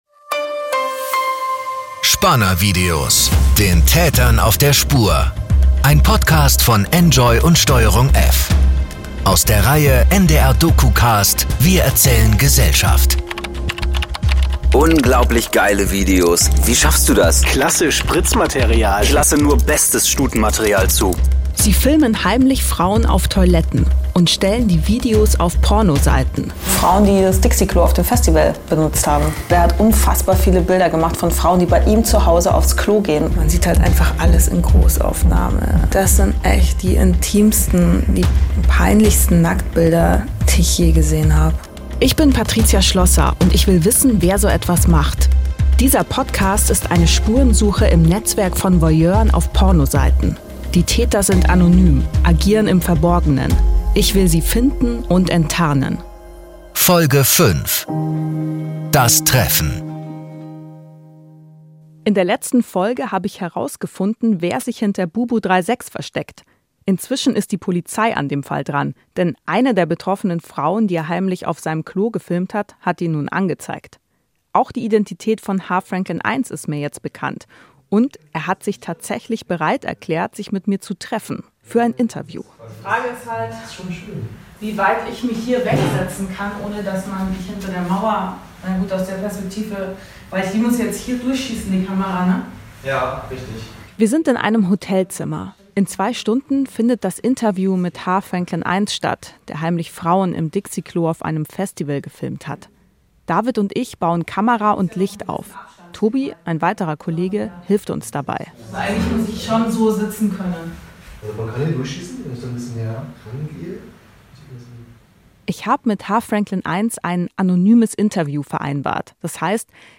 True Crime